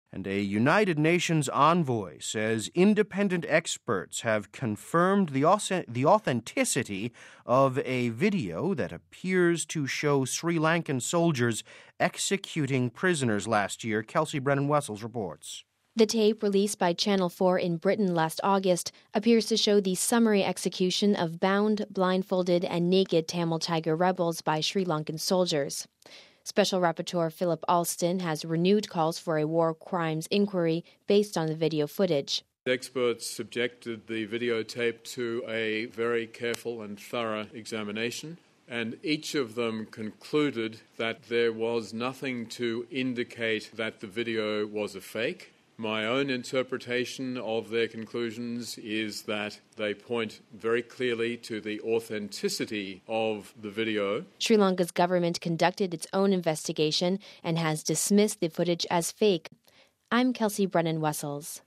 (8 Jan 10 - RV) A United Nations envoy says independent experts have confirmed the authenticity of a video that appears to show Sri Lankan troops executing prisoners last year. We have this report…